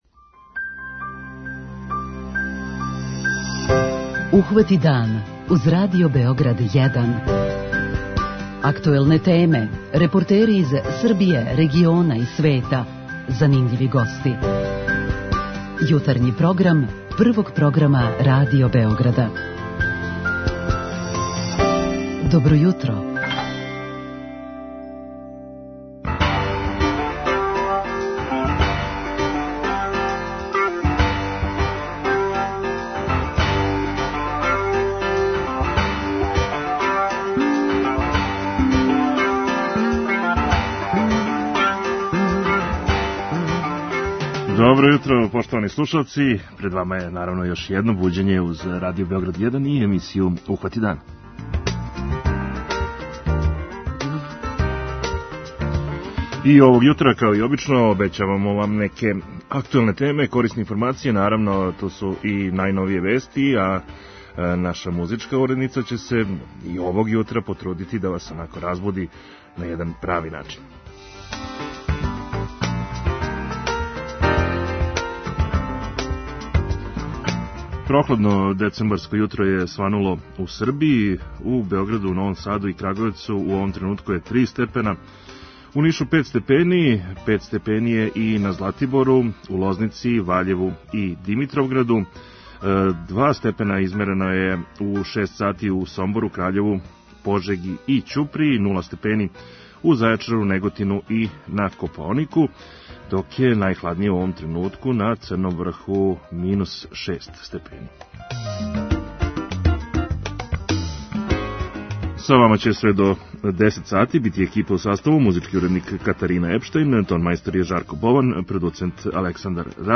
Група аутора Јутарњи програм Радио Београда 1!